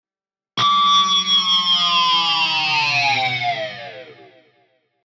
ロックな効果音をご自由にダウンロードして下さい。
Distortion Sound Guitar
Distortionギュゥーン01 98.89 KB